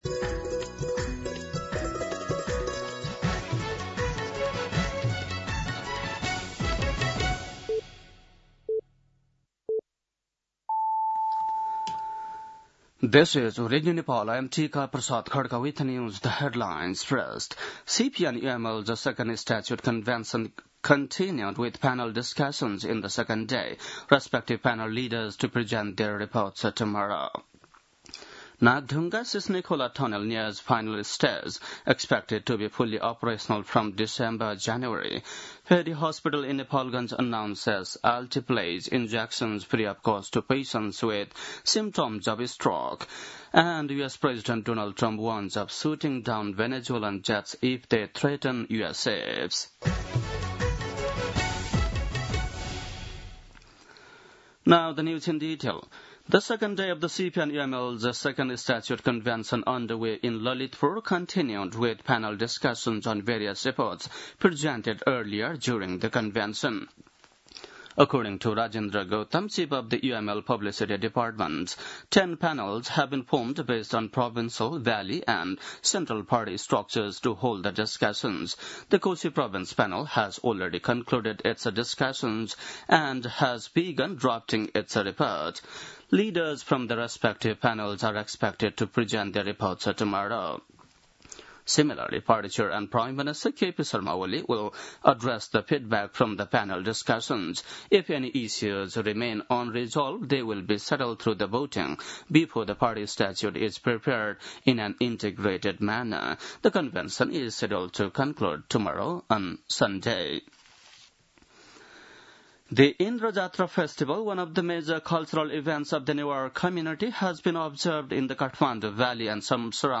बेलुकी ८ बजेको अङ्ग्रेजी समाचार : २१ भदौ , २०८२